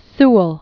(səl), Samuel 1652-1730.